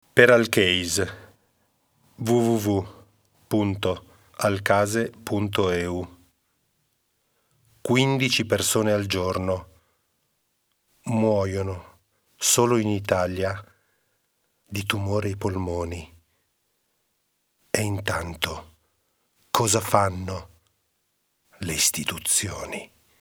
Il nuovo audiolibro
Tre brani tratti dall’audiolibro